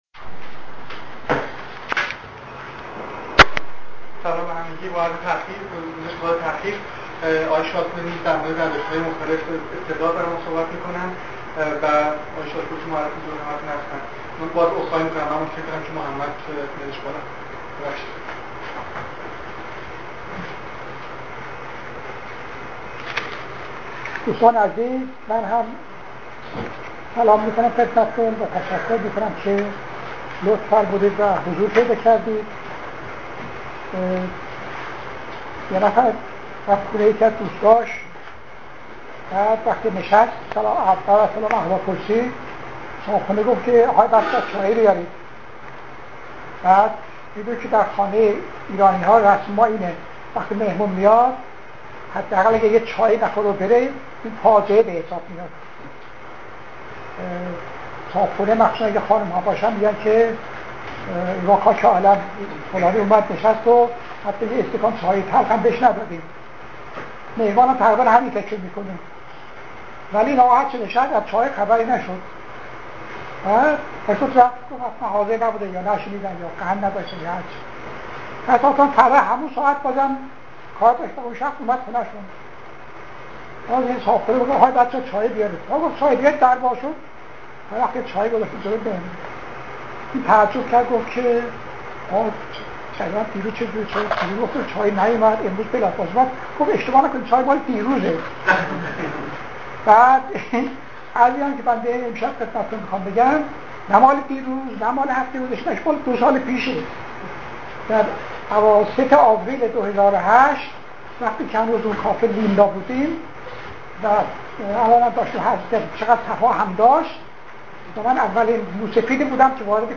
We are a group of mostly Persian young students, scholars and professionals who gather every Thursday evening around 19h00 in a café to discuss various subjects and share different ideas, ranging vastly from history to science, from literature to fine arts.
Afterwards, the evening turns into a discussion forum